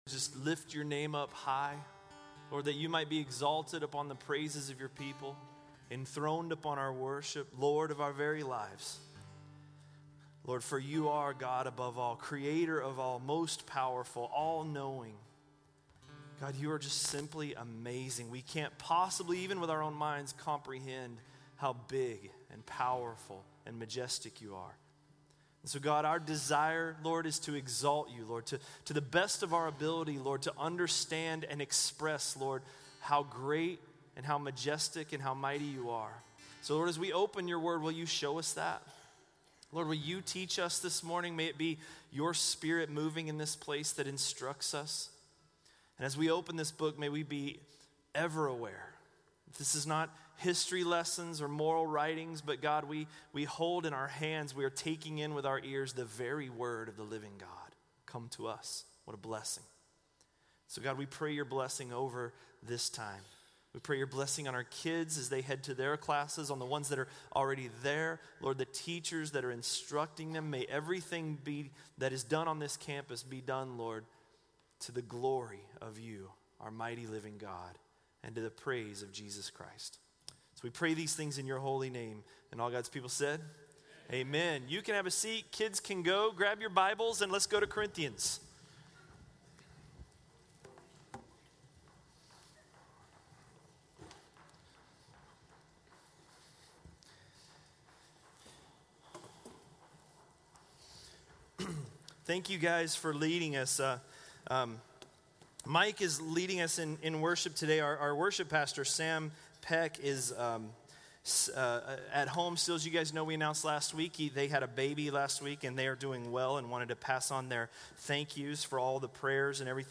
A message from the series "1 Corinthians." 1 Corinthians 10:1–10:14